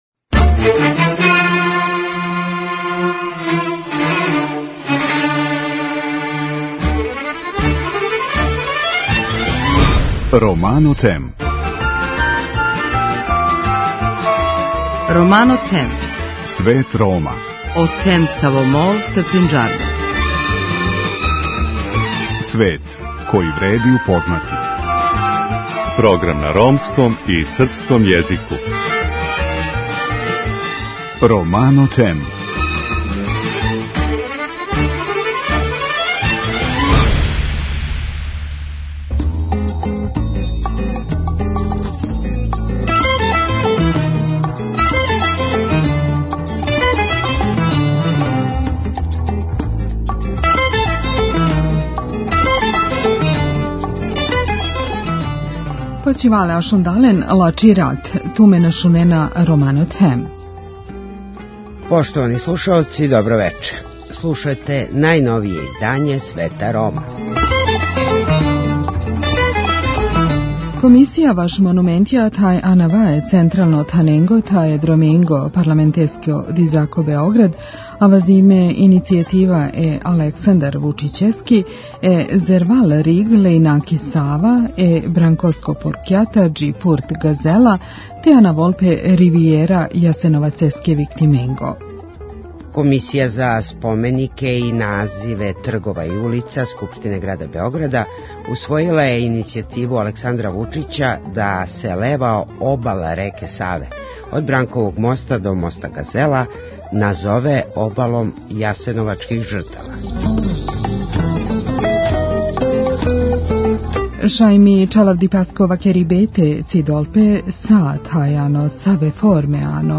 Емисија свакодневно доноси најважније вести из земље и света на ромском и српском језику.